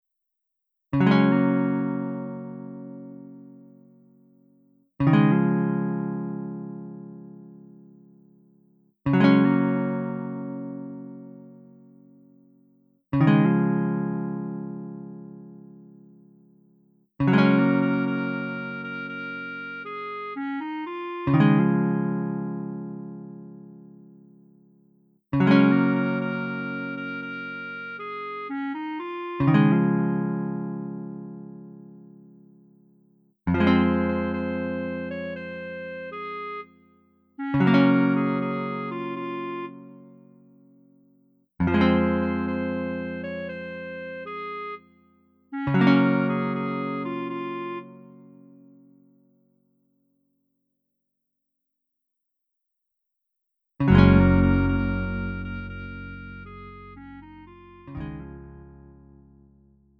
음정 -1키 4:31
장르 구분 Lite MR